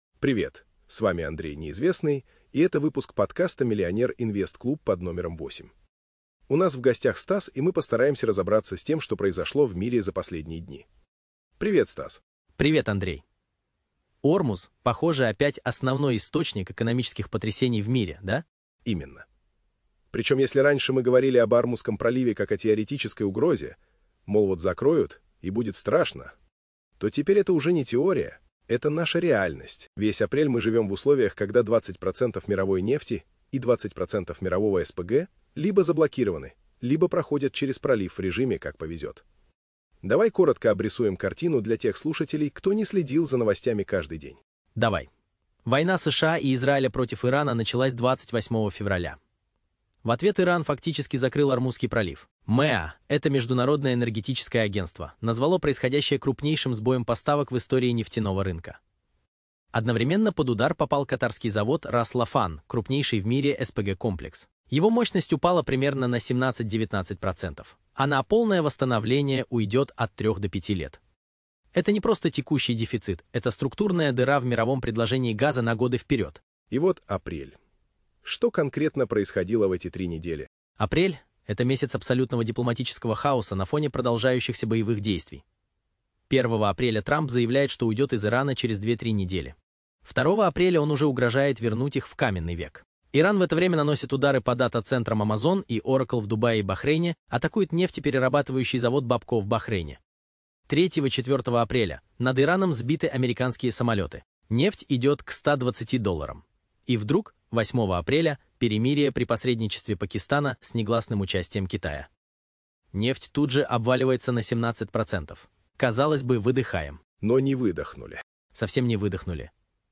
🎙 Эксперт